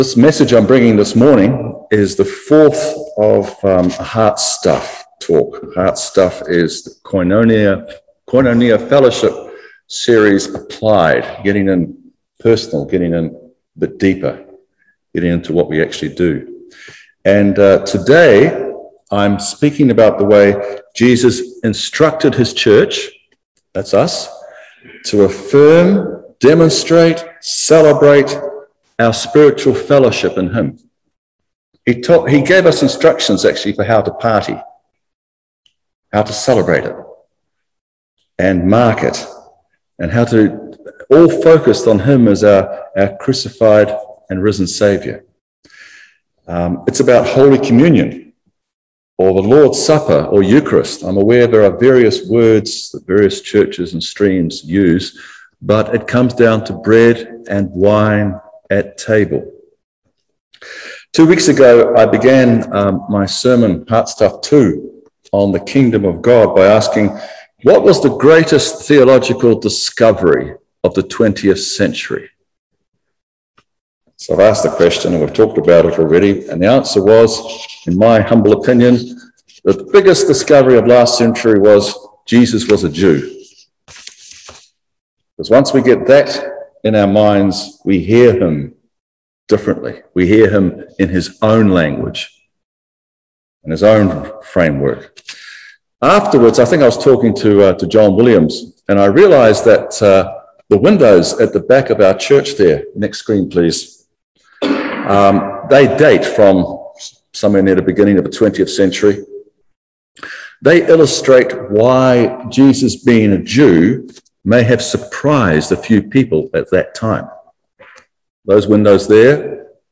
Service Type: Holy Communion